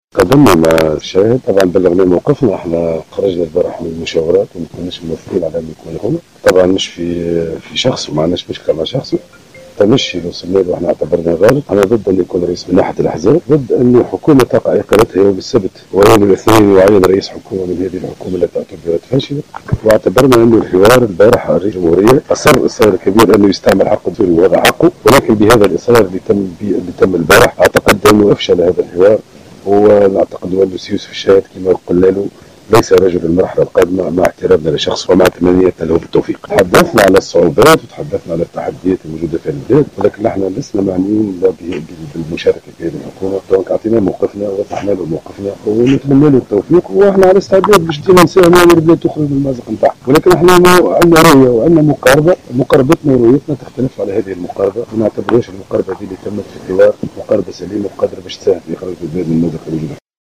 أكد أمين عام حركة الشعب، زهير المغزاوي في تصريح إعلامي صباح اليوم الخميس على هامش لقائه برئيس الحكومة المكلف يوسف الشاهد أنه قام بتليغ الشاهد بموقف الحركة منه موضحا أن هذا الموقف ليس شخصيا وإنما نابع من ايمانهم بأن الشاهد ليس رجل المرحلة القادمة وبأن رئيس الحكومة لا يجب أن يكون من داخل أي حزب سياسي.